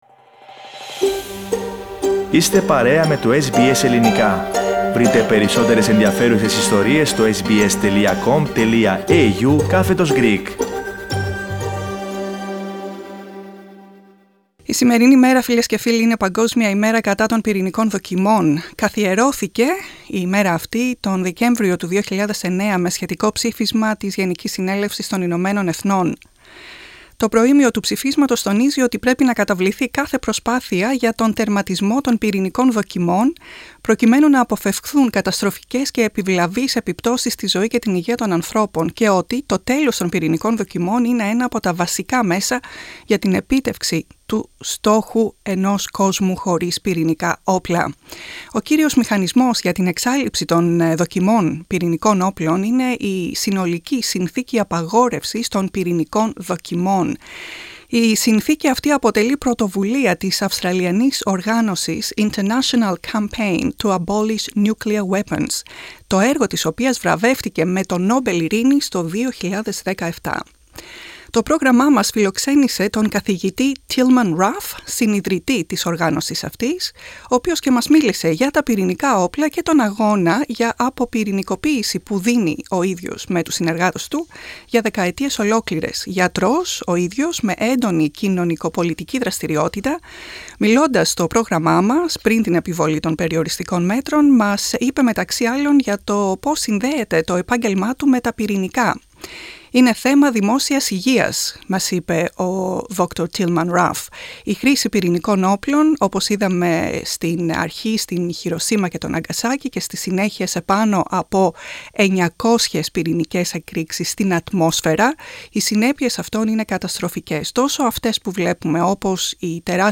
Ο Αυστραλός Νομπελίστας Tillman Ruff μιλώντας στο SBS Greek είπε ότι η αποπυρηνικοποίηση είναι θέμα δημόσιας υγείας.
at SBS's studios